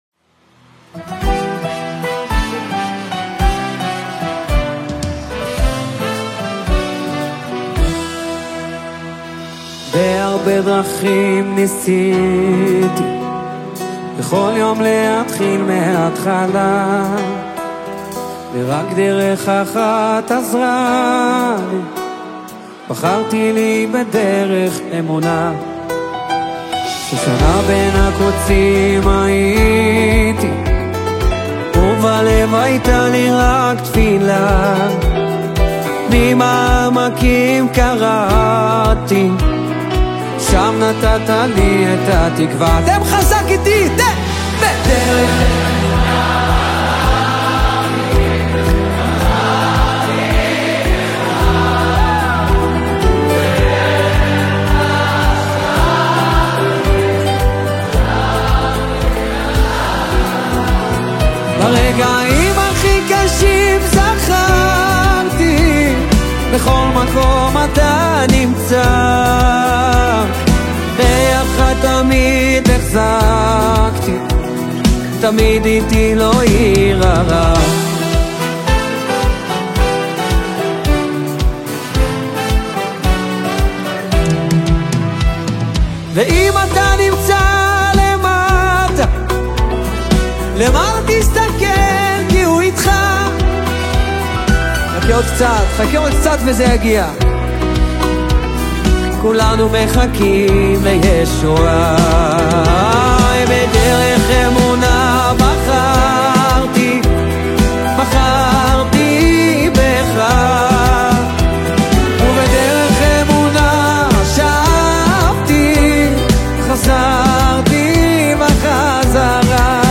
בנייני האומה בין הזמנים